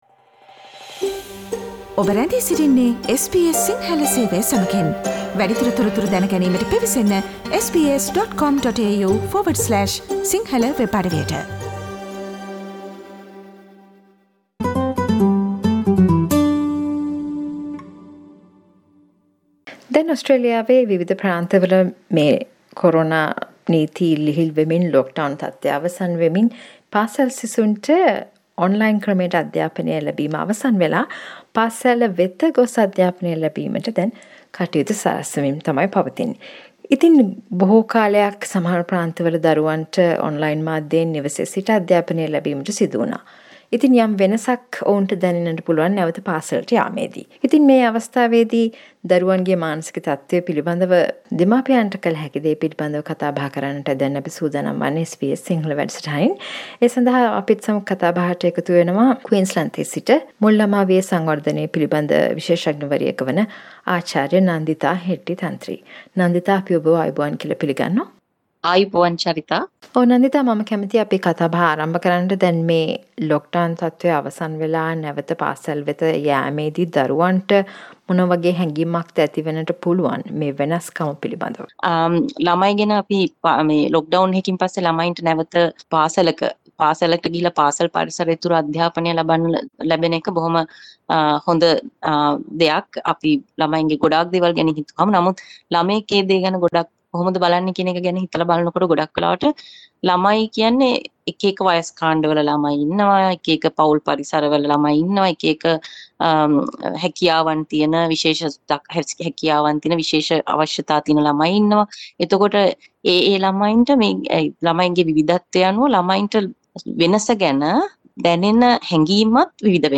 ඕස්ට්‍රේලියාවේ බොහෝ ප්‍රාන්ත වල නැවත පාසල් තුල අධ්‍යාපනය ආරම්භ වීම සමග දරුවන් මුහුණ දෙන ගැටළු පිළිබද SBS සිංහල ගෙන එන සාකච්චාවට සවන් දෙන්න ඉහත ඡයාරූපය මත ඇති speaker සලකුණ මත ක්ලික් කරන්න.